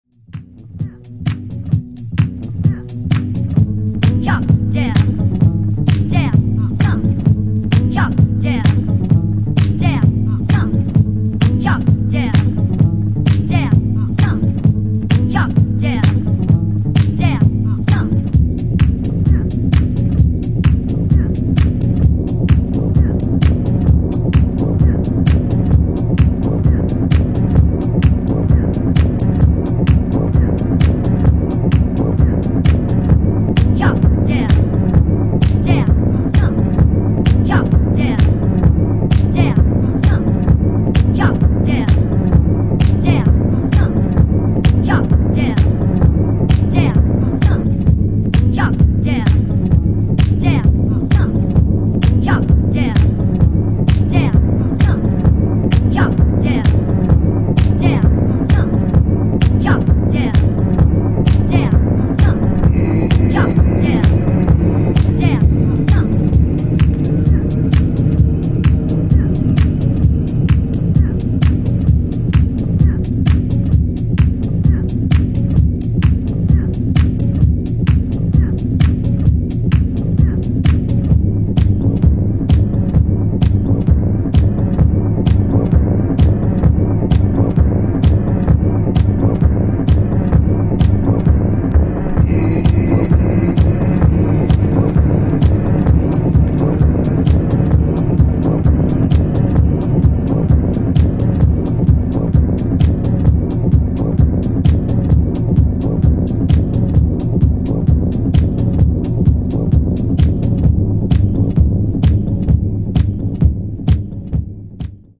supplier of essential dance music
Disco House